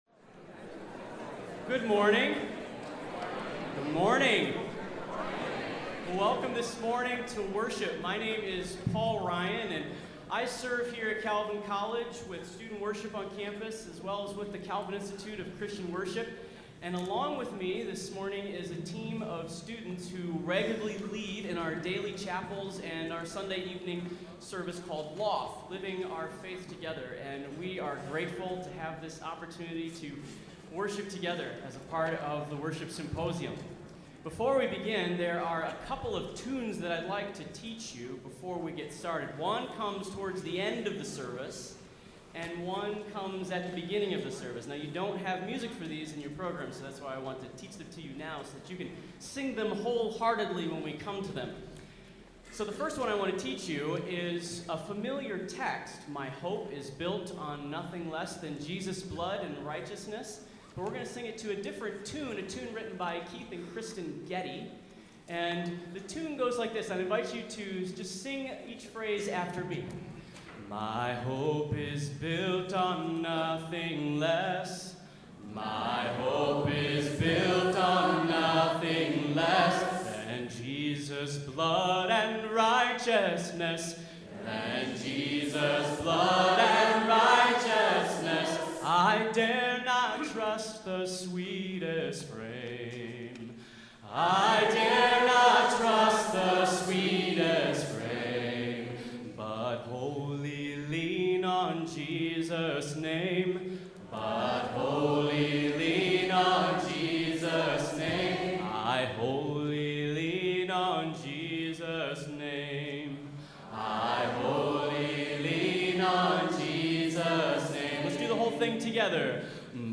The LOFT services follow the movements of praise, confession and assurance, intercession, listening to God's Word, and responding with acts of dedication and faith. The pattern is consistent from week to week but is often experienced as a time of free-flowing worship that makes use of visual art, dramatic readings, dance, prayer, and music from a variety of sources and in multiple styles.
Details Surpassing Knowledge is a worship service on Philippians 3:1-11, presented at Calvin Symposium on Worship 2011.